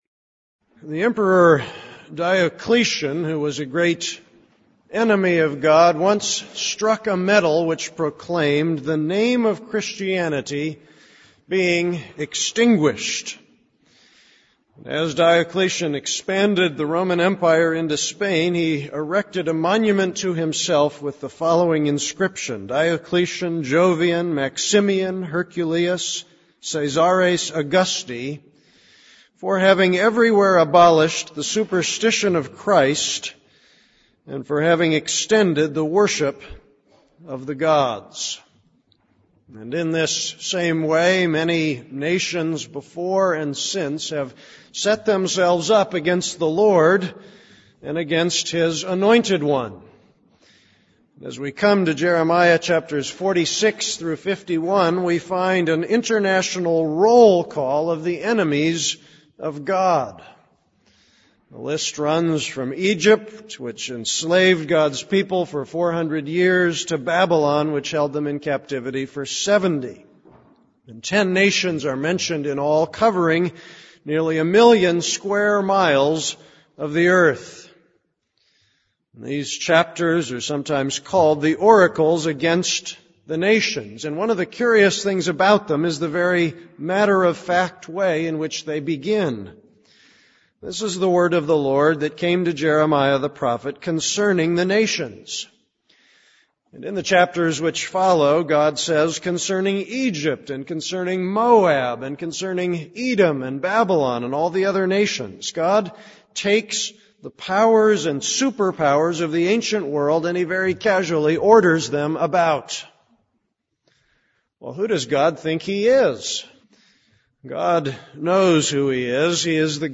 This is a sermon on Jeremiah 46:1-4.